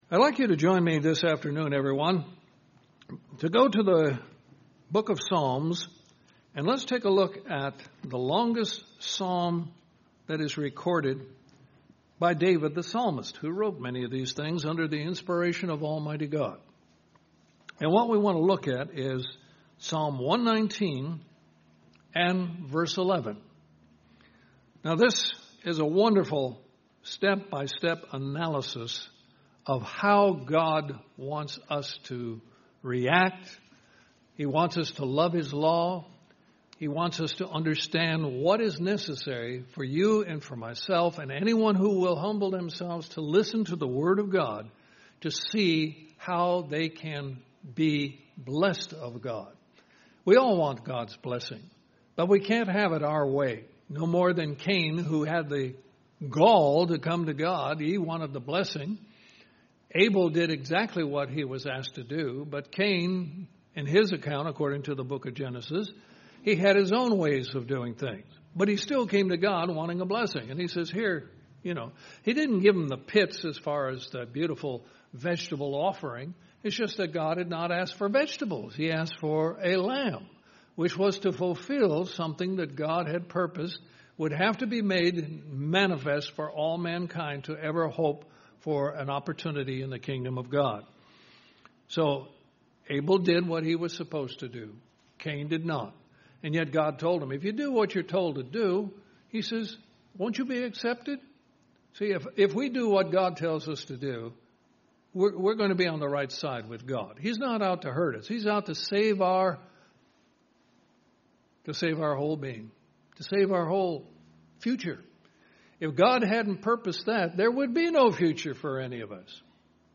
Given in Columbus, GA Central Georgia
Psalm 119:11 UCG Sermon Studying the bible?